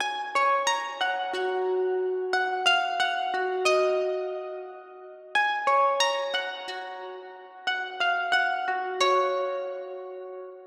Index of /DESN275/loops/Loop Set - Spring - New Age Ambient Loops/Loops
Generosity_90_B_Harp.wav